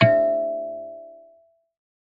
kalimba2_wood-E4-mf.wav